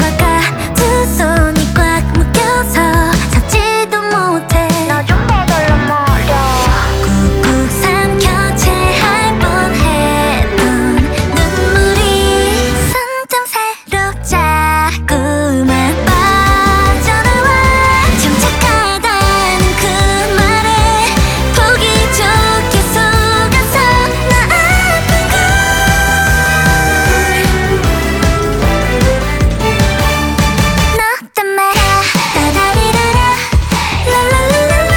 K-Pop Pop
Жанр: Поп музыка